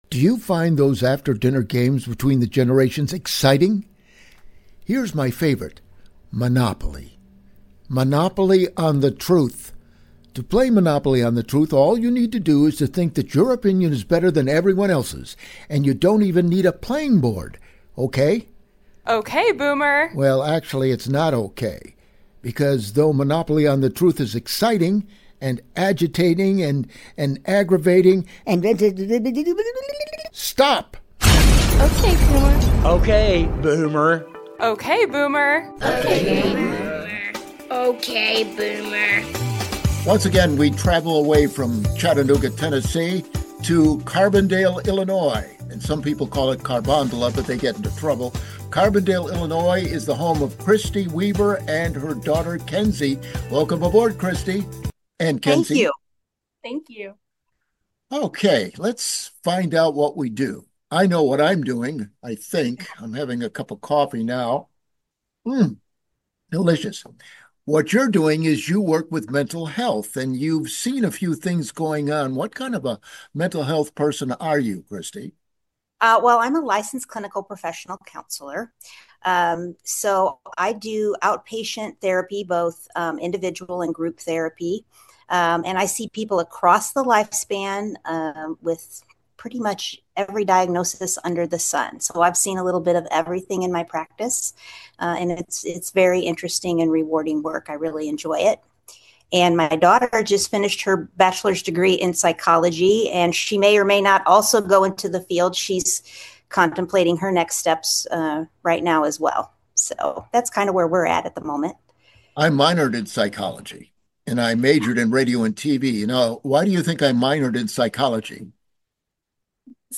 What happens when a Boomer, a Gen X professional counselor, and a Gen Z psychology graduate sit down for coffee and talk about… everything on OK Boomer Channel?
From family conflicts caused by political polarization to the dangers of believing you own a “Monopoly on the Truth,” this discussion is honest, insightful, and surprisingly funny.